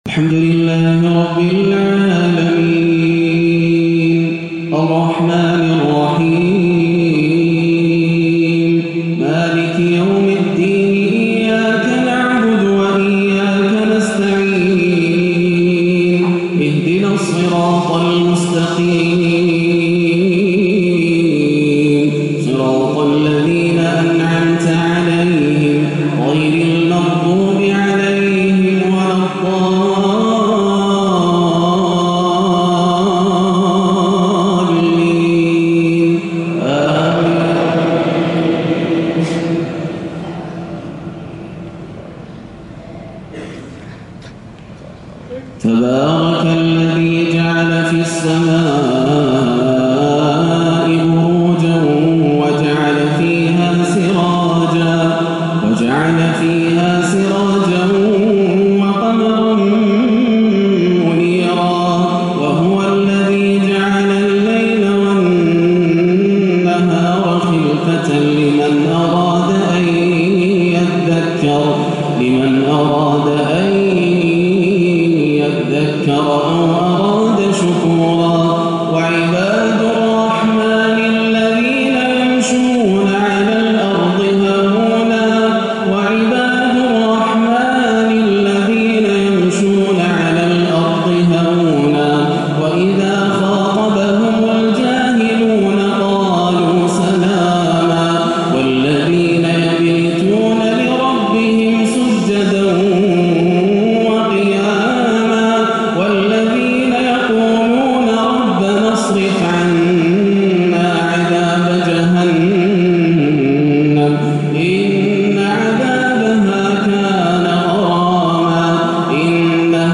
(وعباد الرحمن الذين يمشون على الأرض هونا) تلاوة كردية خاشعة لأوخر الفرقان - صلاة الجمعة 15-7 > عام 1437 > الفروض - تلاوات ياسر الدوسري